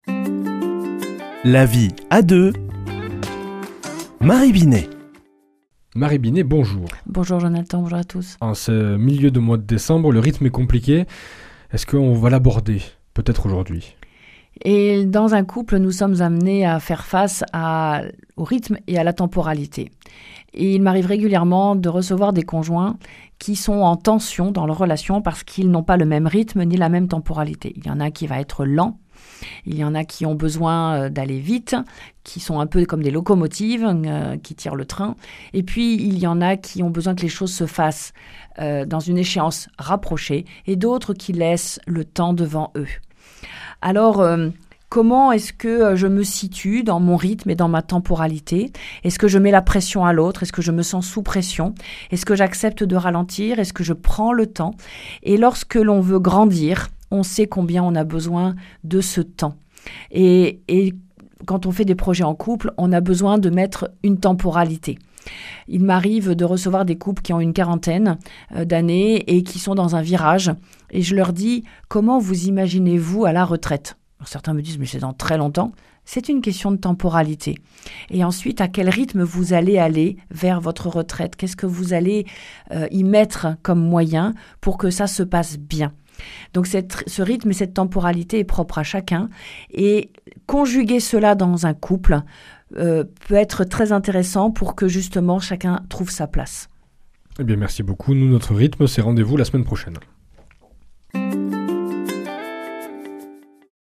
mardi 16 décembre 2025 Chronique La vie à deux Durée 4 min